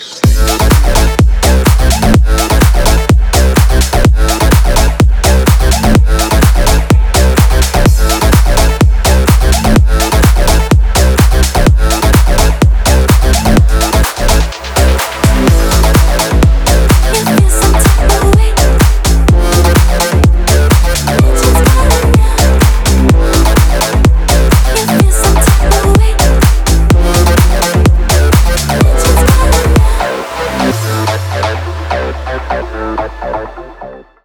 Архив Рингтонов, Клубные рингтоны